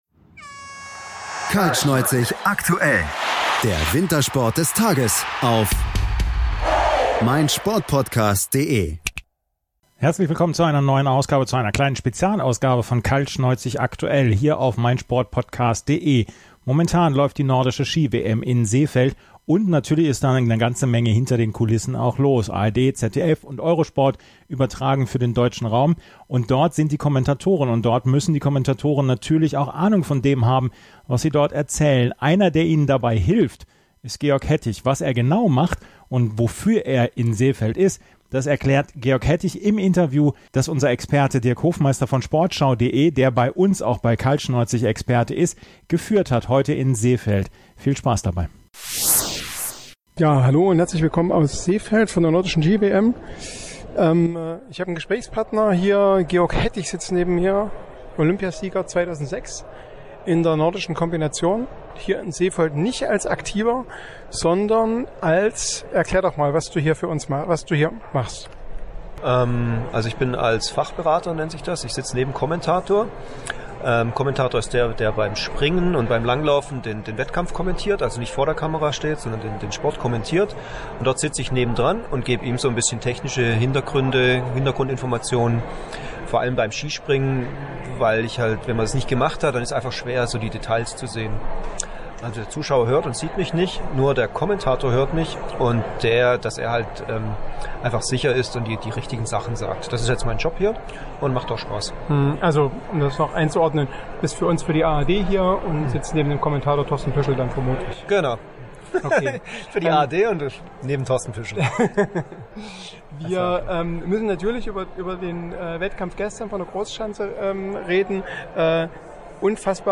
Das aufgezeichnete Interview hört ihr hier bei Kaltschnäuzig.
georg-hettich-im-interview.mp3